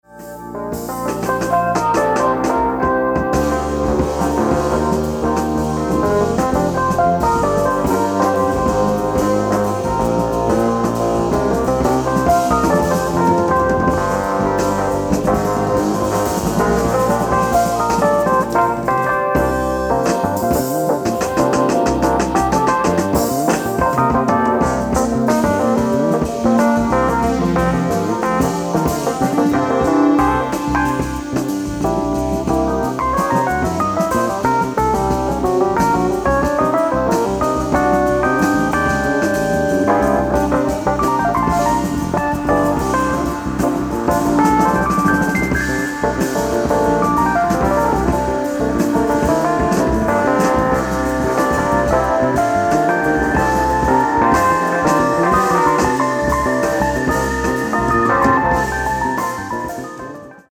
piano, fender rhodes, synthesizers
fretless electric bass
drums